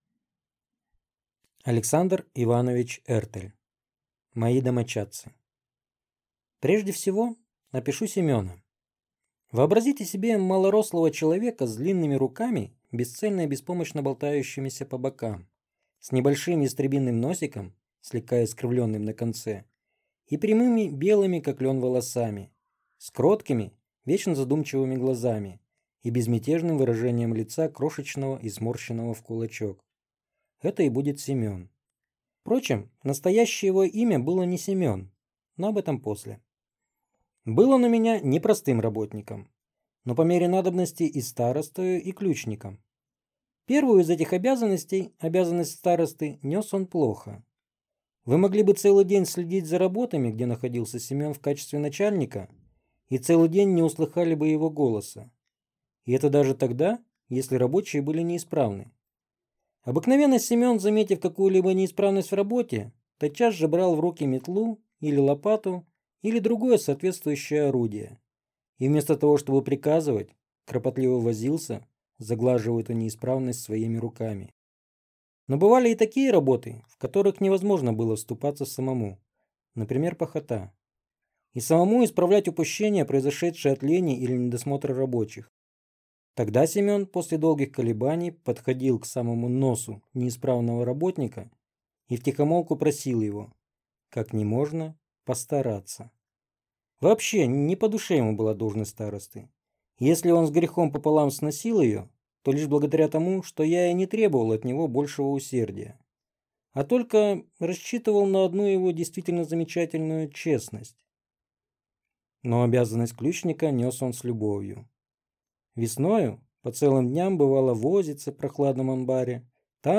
Аудиокнига Мои домочадцы | Библиотека аудиокниг